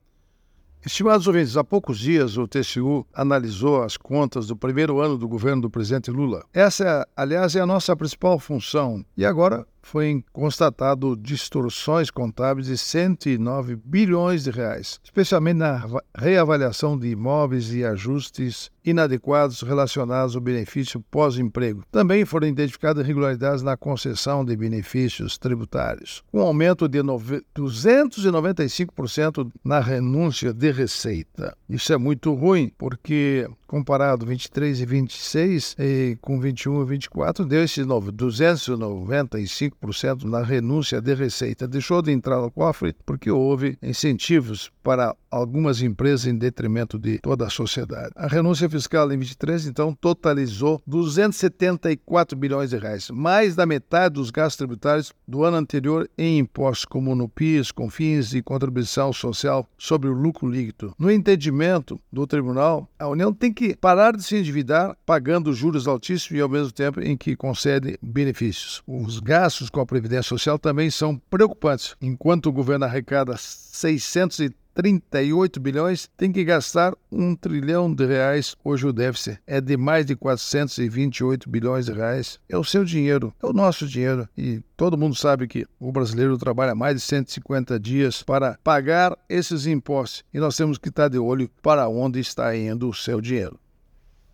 É o assunto do comentário do ministro do Tribunal de Contas da União, Augusto Nardes, desta terça-feira (09/07/24), especialmente para OgazeteirO.